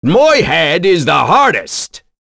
Phillop-Vox_Skill2.wav